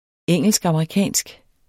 Udtale [ ˈεŋˀəlsgɑmɑiˈkæˀnsg ]